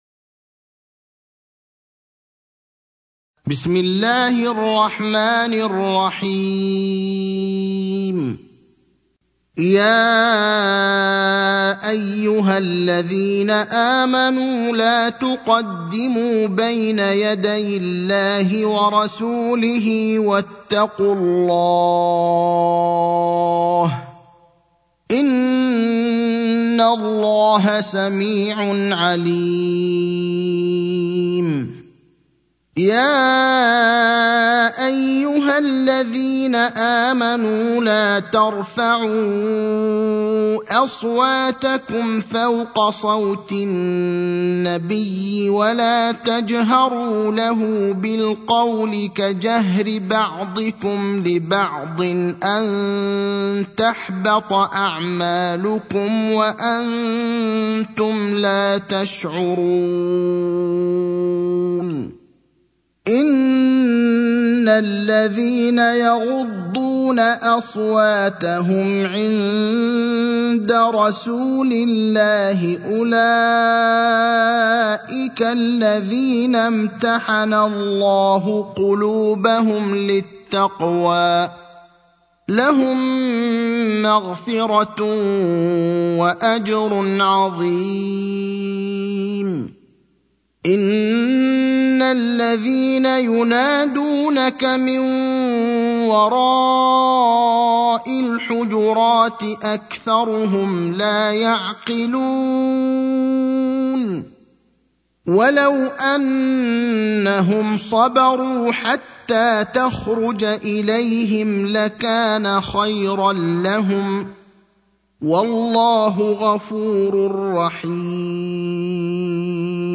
المكان: المسجد النبوي الشيخ: فضيلة الشيخ إبراهيم الأخضر فضيلة الشيخ إبراهيم الأخضر سورة الحجرات